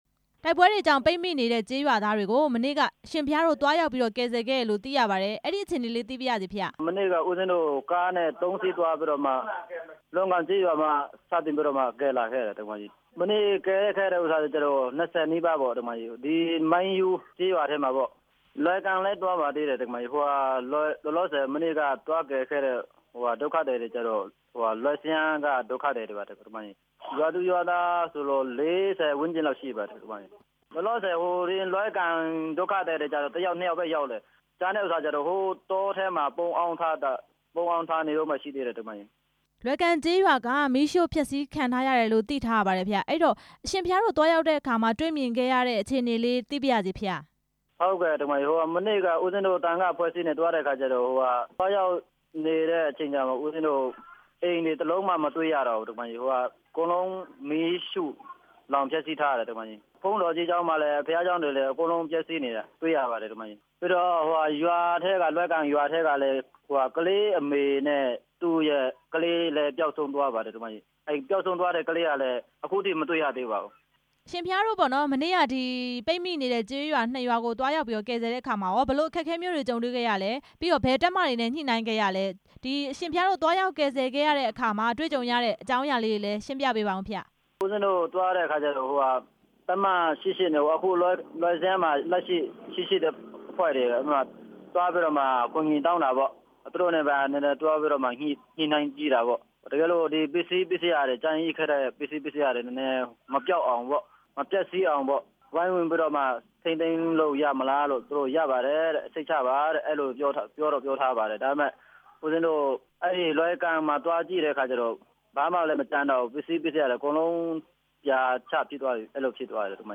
ပိတ်မိနေတဲ့ ရွာသားတွေကို သွားရောက်ခေါ်ဆောင်တဲ့ အကြောင်း မေးမြန်းချက်